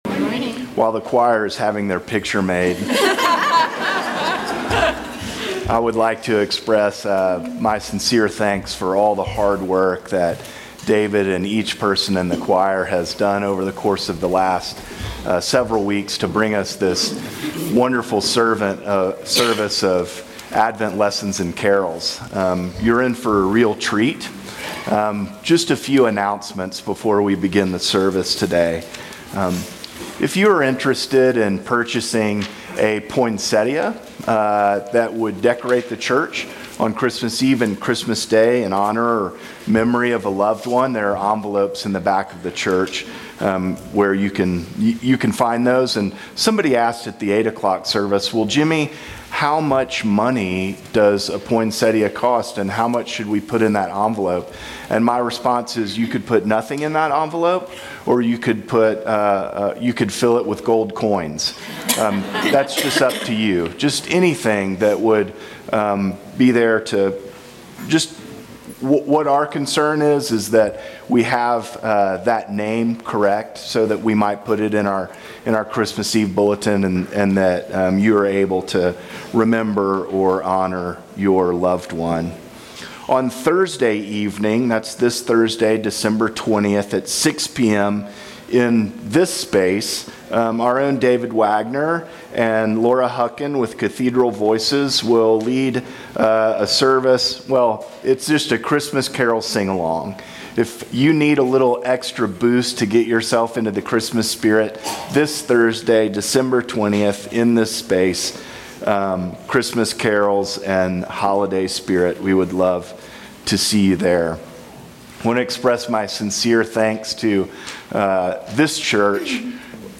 Join us for nine Bible readings (or lessons), that tell the Christmas story, with carols between each lesson.